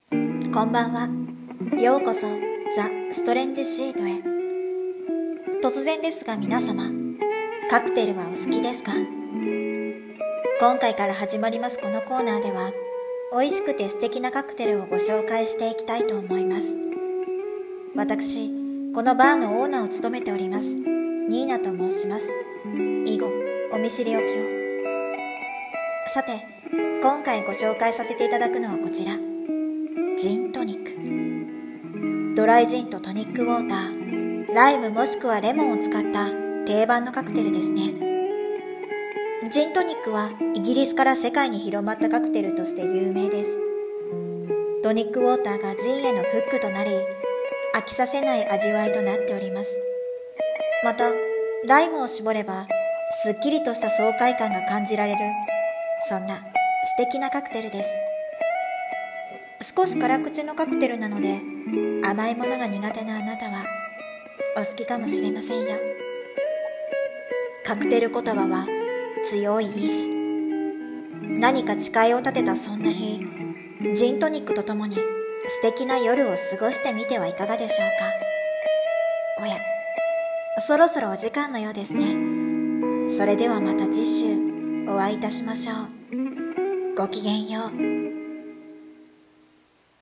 【ラジオ風】カクテル図鑑 001